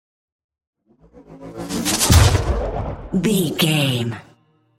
Chopper whoosh to hit engine
Sound Effects
Atonal
No
dark
high tech
intense
tension
woosh to hit